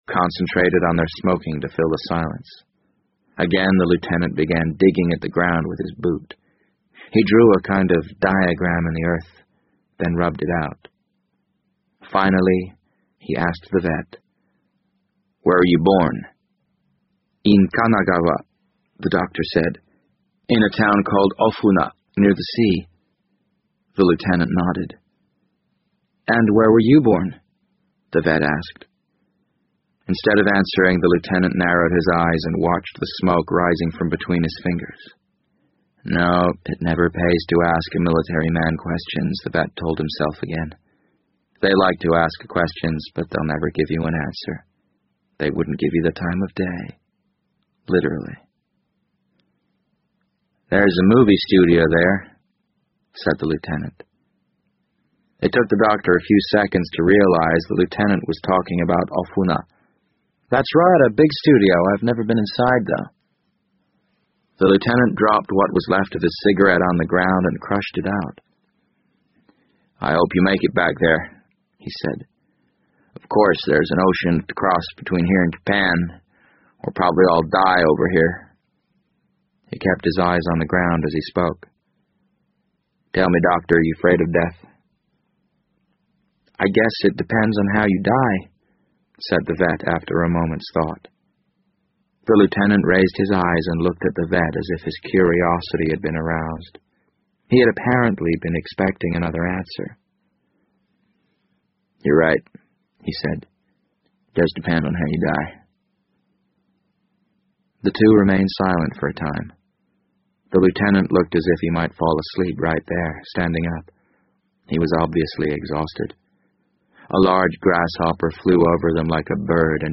BBC英文广播剧在线听 The Wind Up Bird 013 - 8 听力文件下载—在线英语听力室